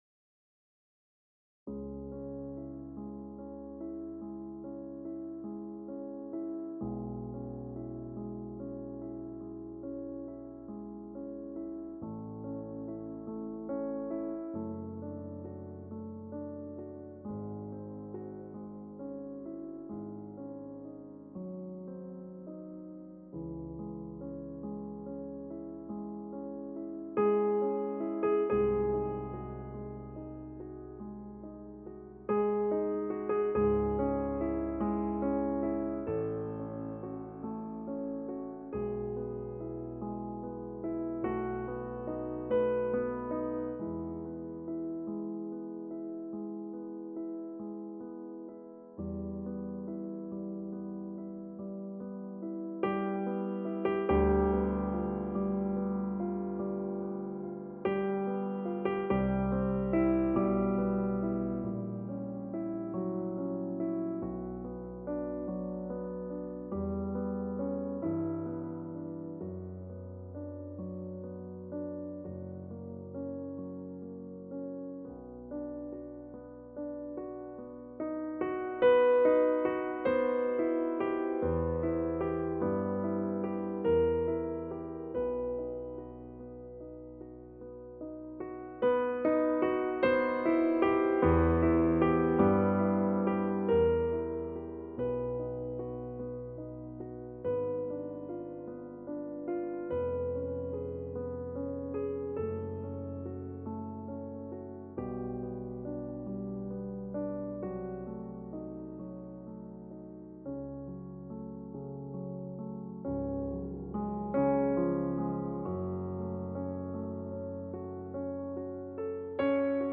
Piano_sonata_No14-04.mp3